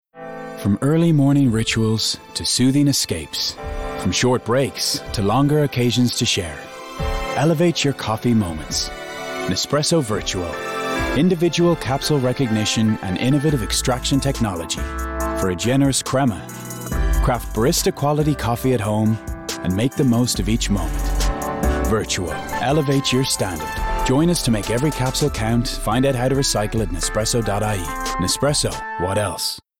20/30's Irish, Natural/Compelling/Authentic
Commercial Showreel